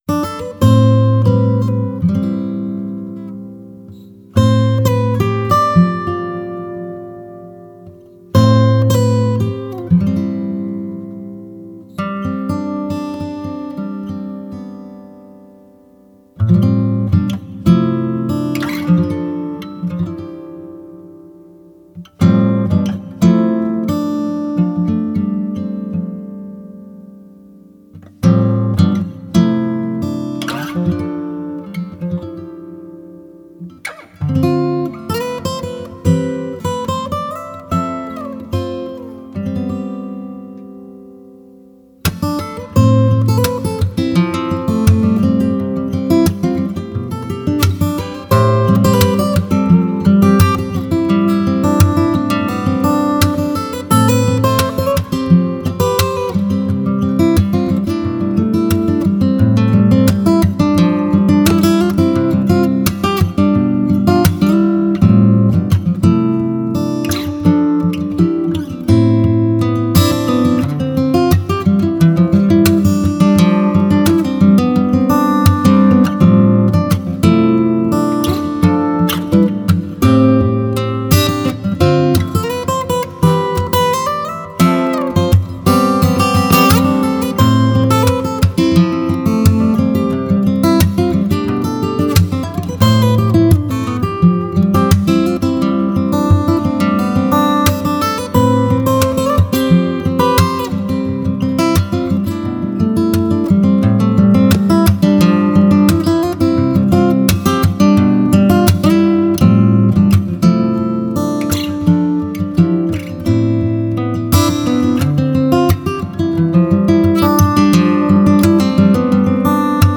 Гитара / Мелодии